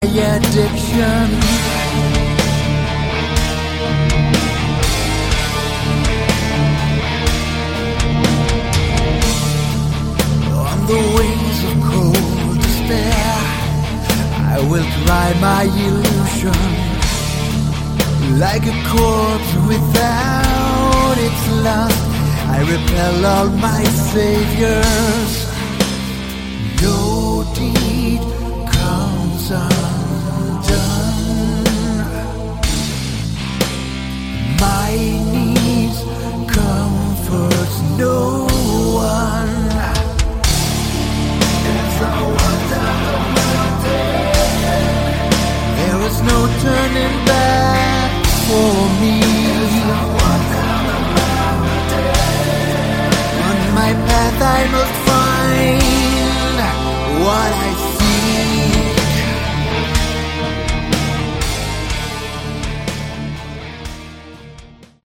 Category: Hard Rock/Melodic Metal
vocals, keyboards
guitars
bass
drums